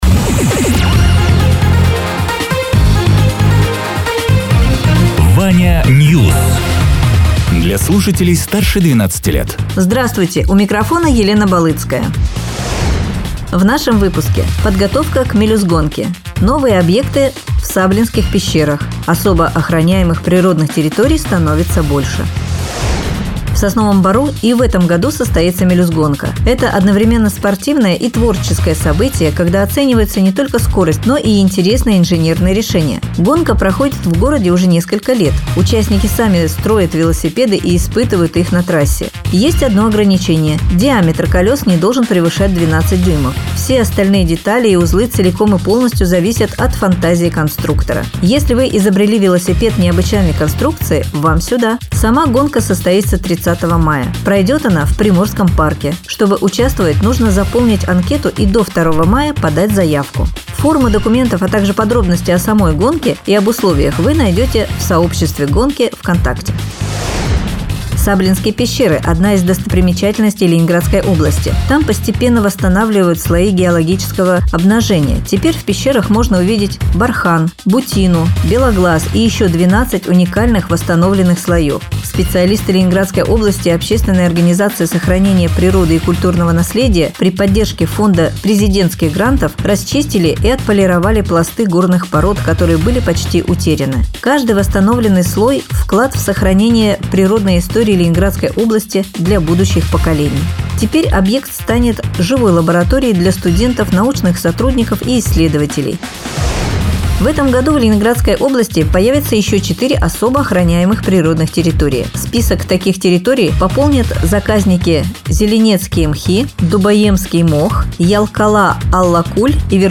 Радио ТЕРА 01.04.2026_12.00_Новости_Соснового_Бора